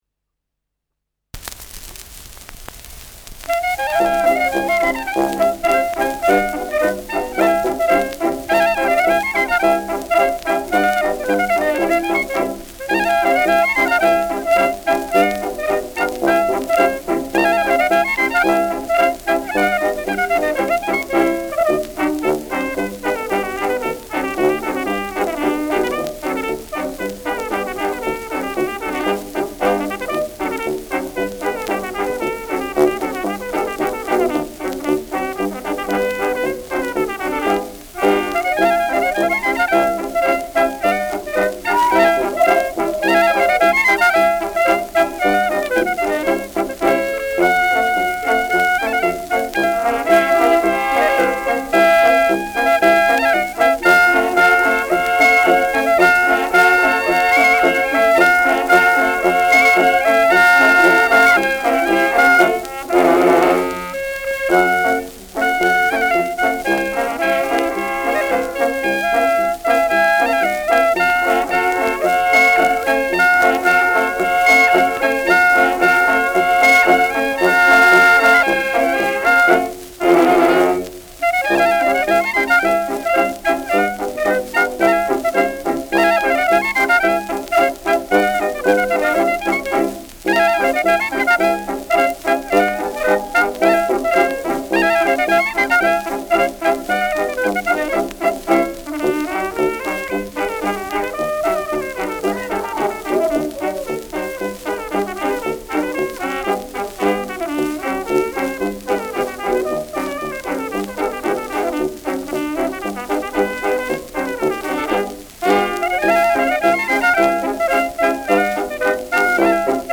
Schellackplatte
präsentes Rauschen
Dachauer Bauernkapelle (Interpretation)
[München] (Aufnahmeort)